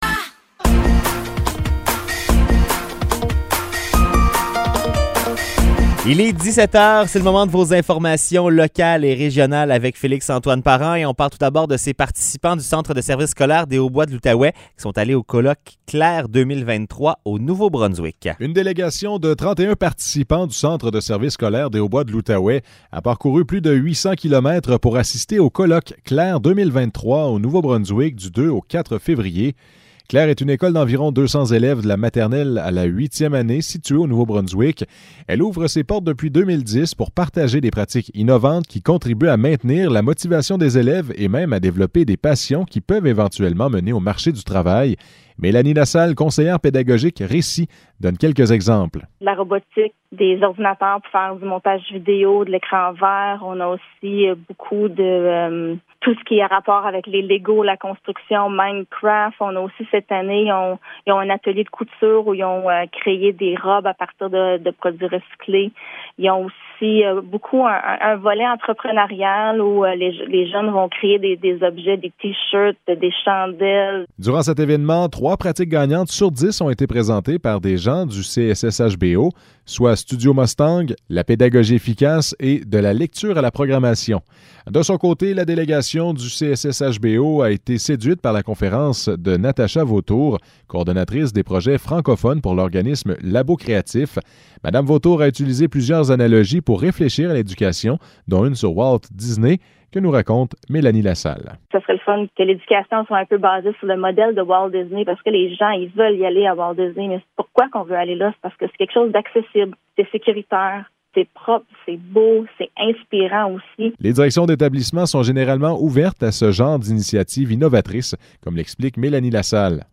Nouvelles locales - 14 février 2023 - 17 h